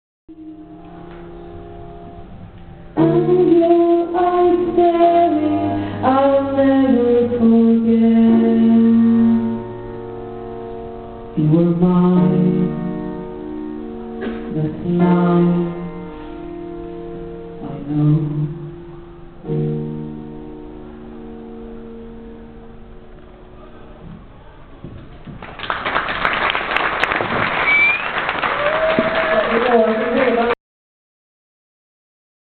This was a marathon performance.